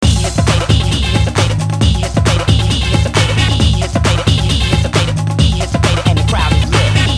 breakbeat house track